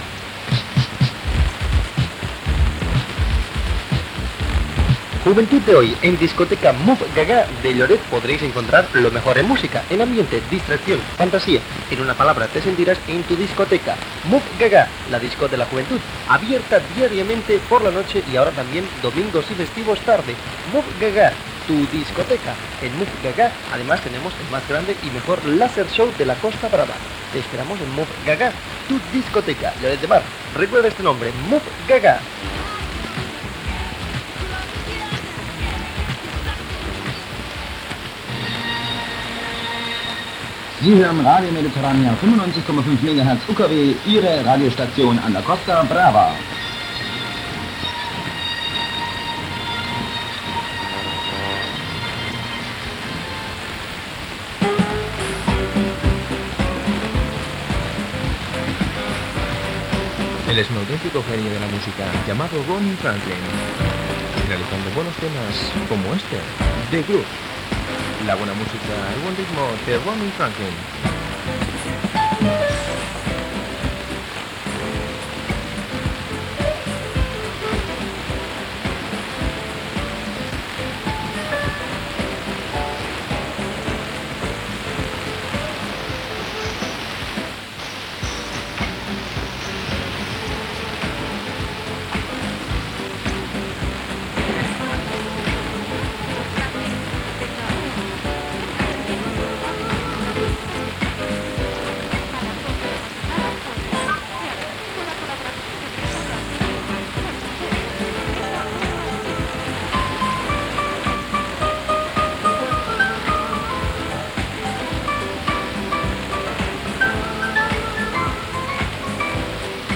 Publicitat, identificació en alemany, presentació d'un tema musical, identificació en català i nou tema musical.
Musical
FM